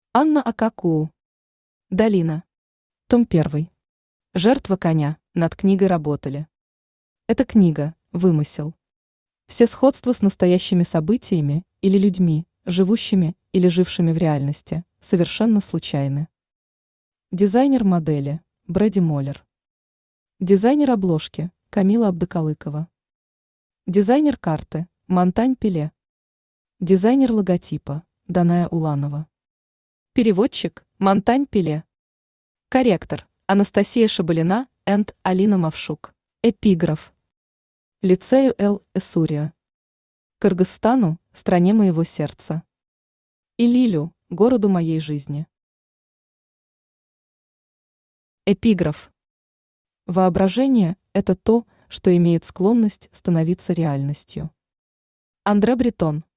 Жертва коня Автор Анна Ак Куу Читает аудиокнигу Авточтец ЛитРес.